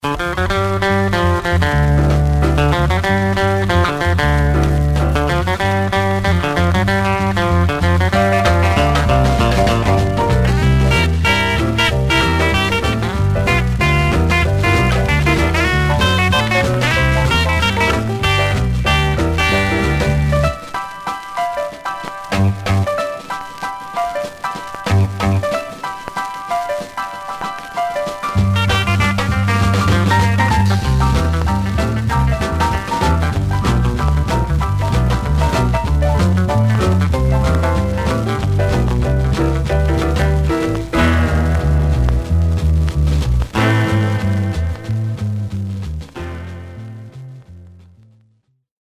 Surface noise/wear Stereo/mono Mono
R & R Instrumental